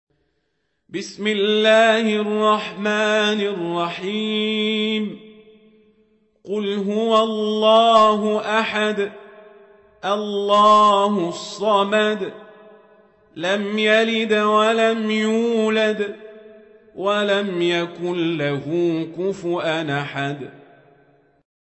سورة الإخلاص | القارئ عمر القزابري